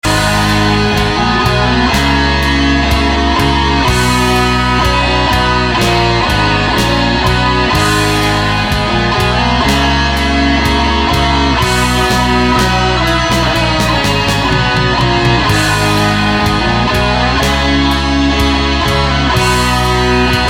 Фолкопаган какой-то О_о